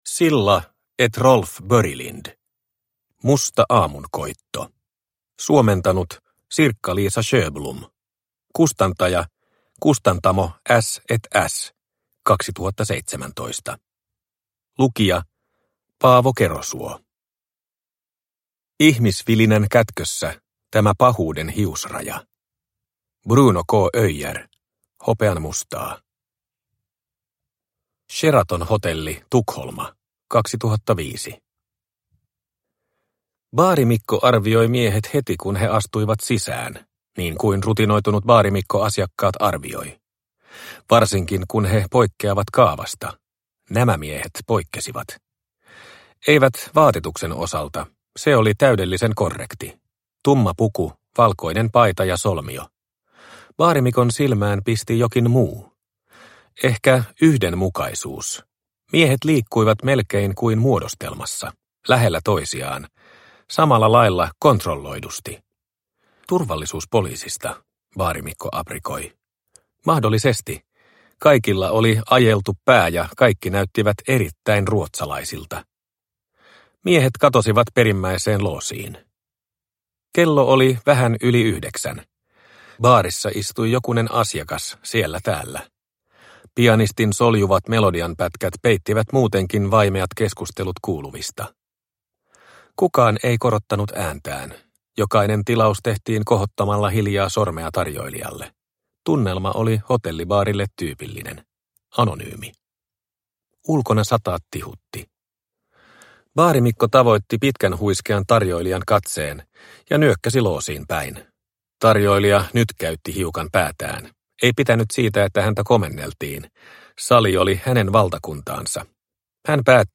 Musta aamunkoitto – Ljudbok – Laddas ner